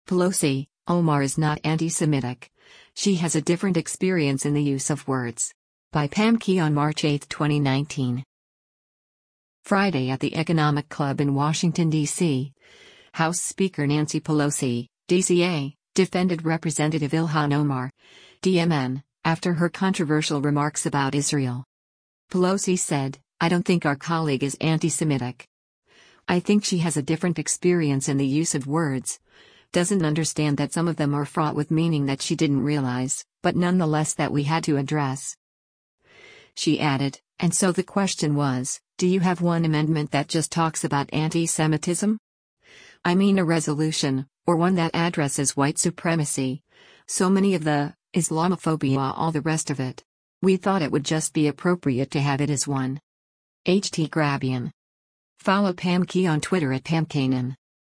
Friday at the Economic Club in Washington D.C., House Speaker Nancy Pelosi (D-CA) defended Rep. Ilhan Omar (D-MN) after her controversial remarks about Israel.